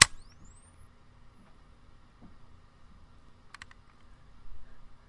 声音包括吱吱作响的地板上的脚步声，电梯乘坐，攀爬金属楼梯。用Tascam DR40录制。